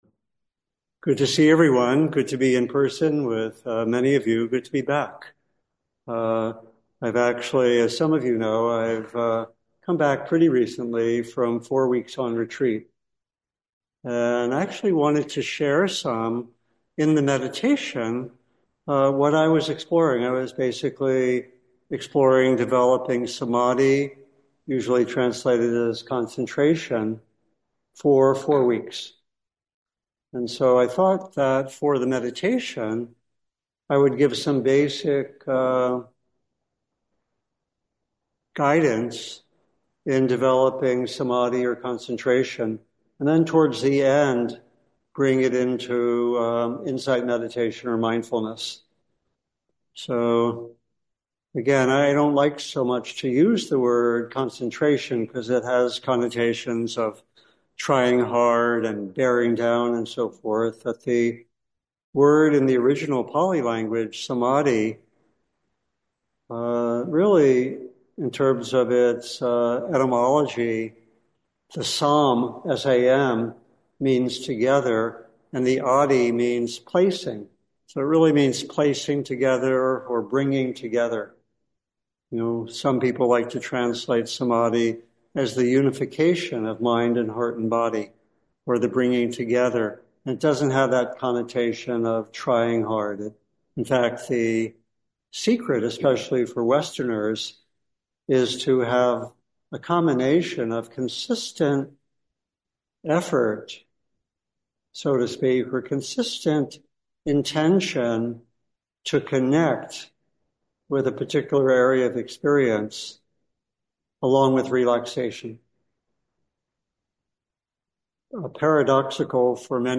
Buddhist Meditation Group